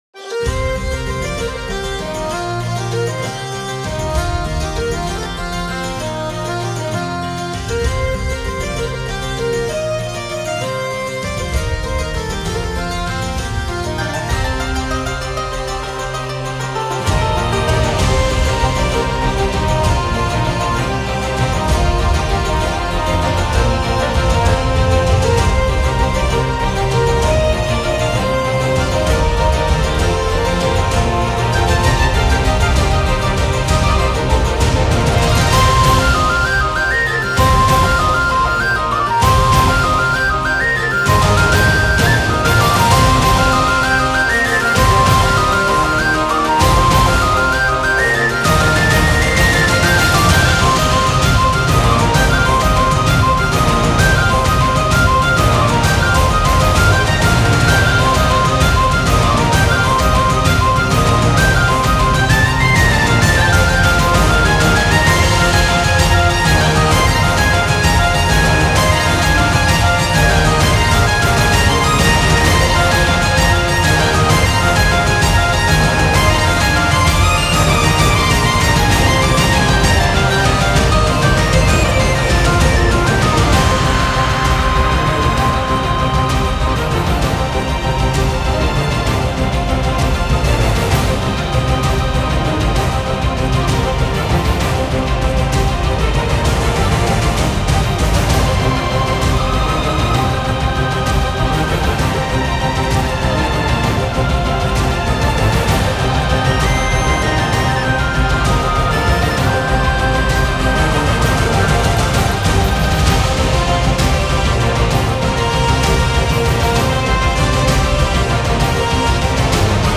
Фолк Рок